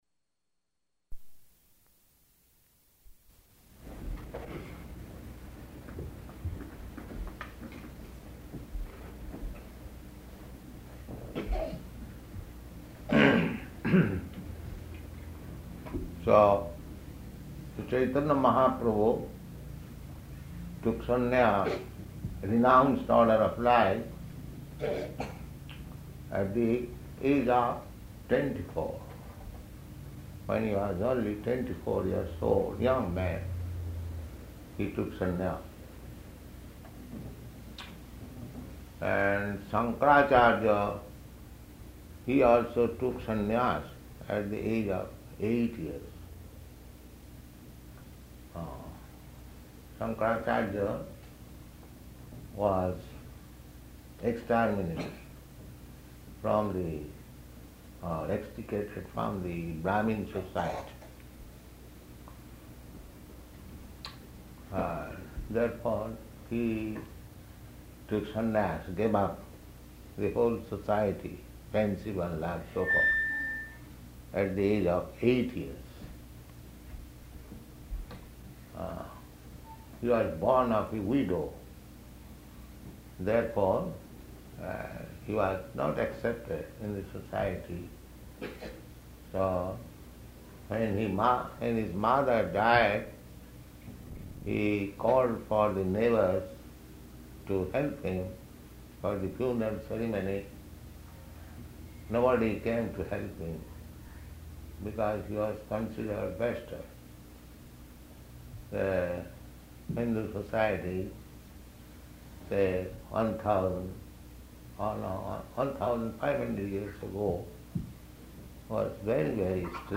Lecture about Māyāvāda Philosophy
Type: Lectures and Addresses
Location: Honolulu